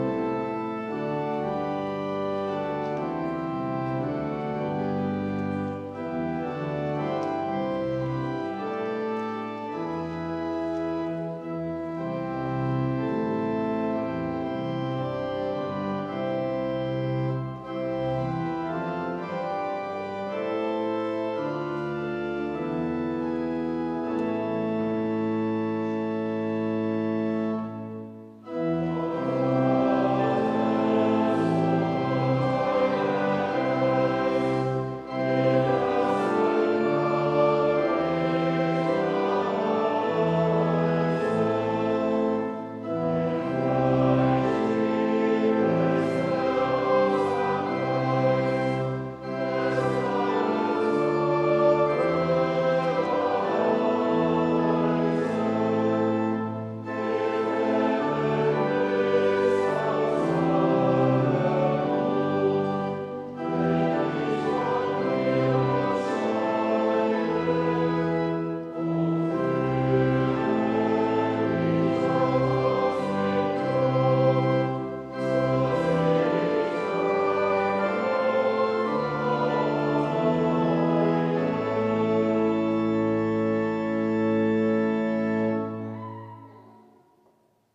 Gott Vater, Sohn und Heilger Geist... (LG 192) Evangelisch-Lutherische St. Johannesgemeinde Zwickau-Planitz
Audiomitschnitt unseres Gottesdienstes am 8. Sonntag nach Trinitatis 2023